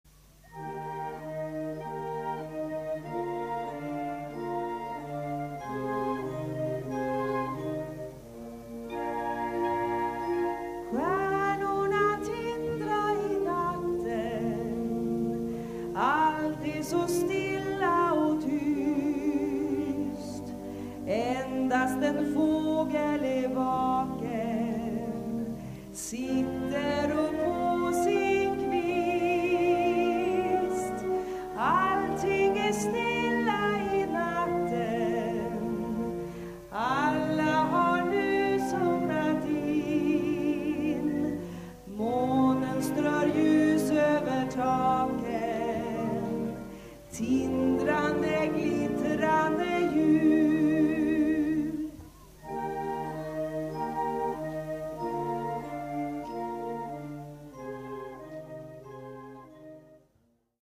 SYMPHONIC BAND
Christmas song, vocal solo ad. lib.